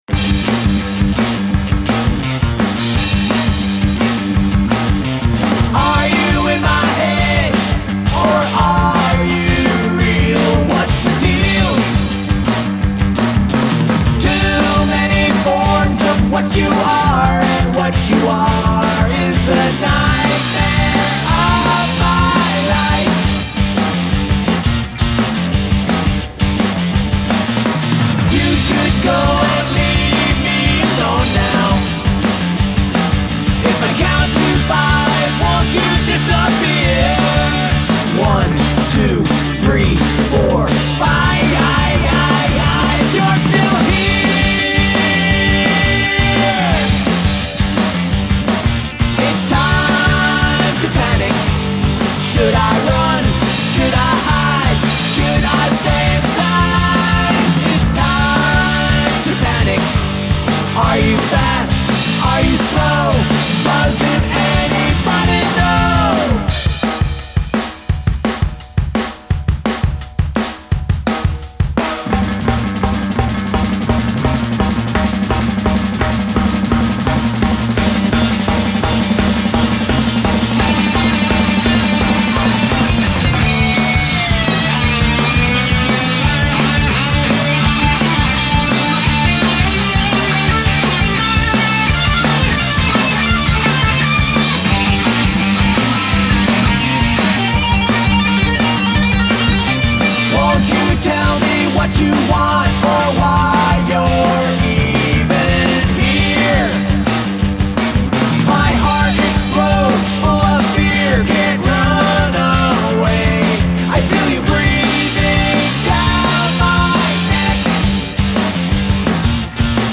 Song must include audible counting
Your drums sound great in this style.